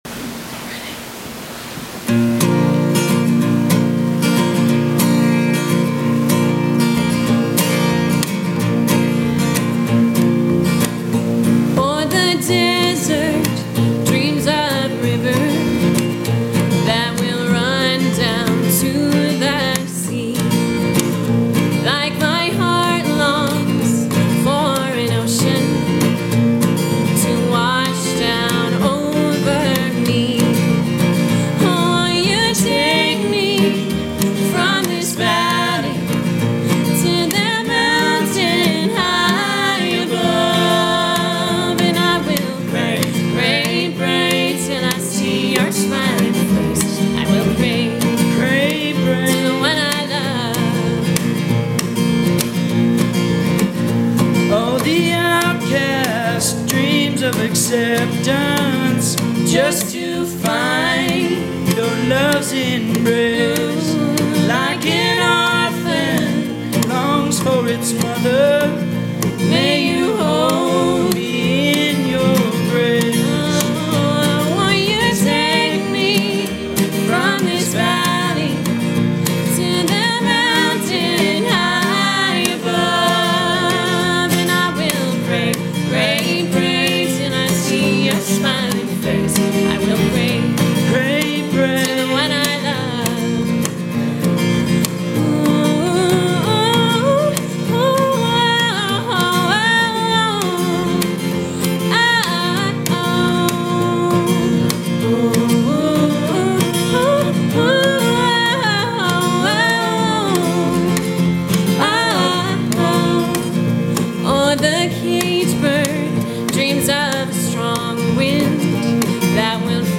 Musical Performance